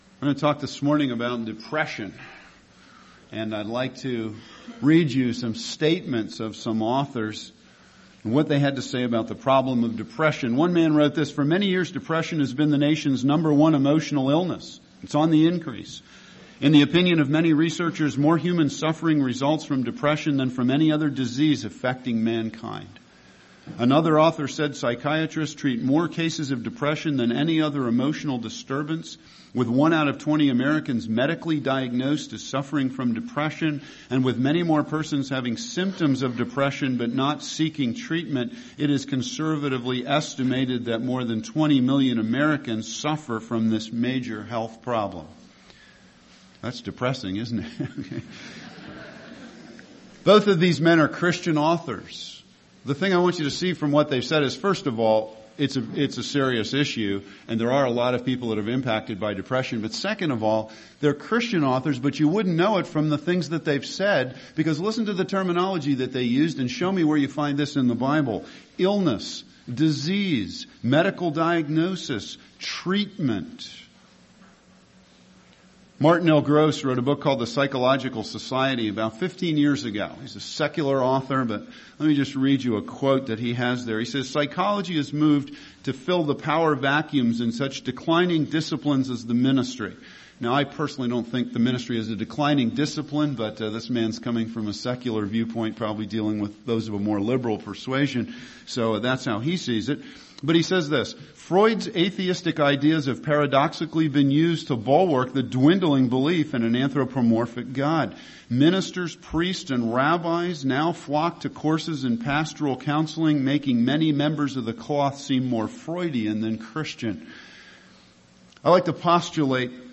II Corinthians 4:1-7. Preached by someone who knows first hand what the depths of depression feels like.